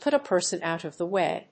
アクセントpùt a person òut of the wáy